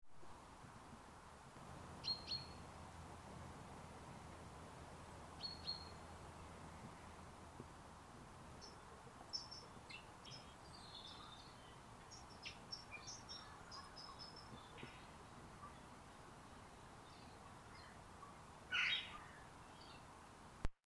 NatureDay2.wav